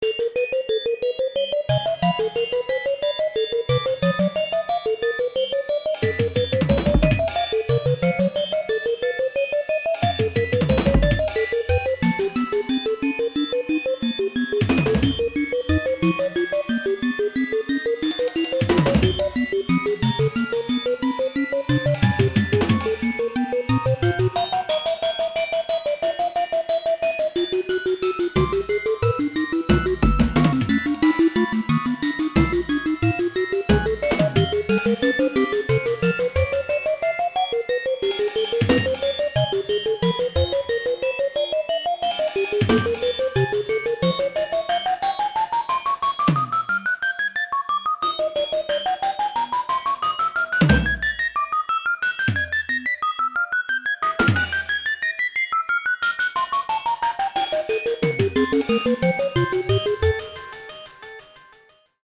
A tense and atmospheric transmission